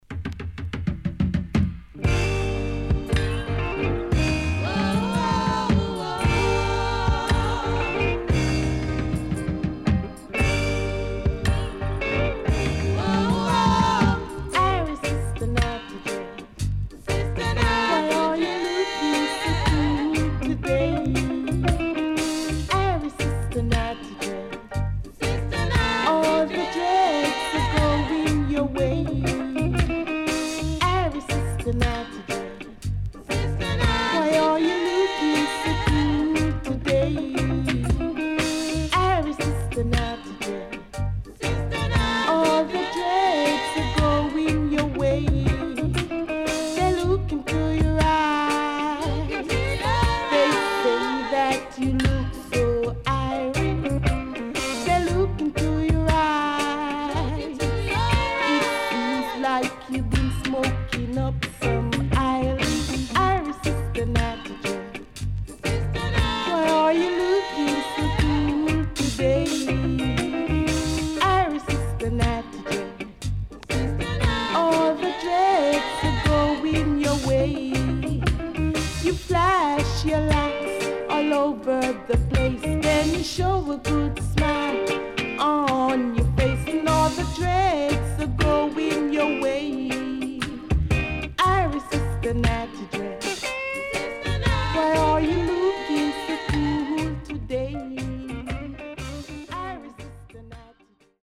【12inch】
Rare.Great Roots Lovers & Dubwise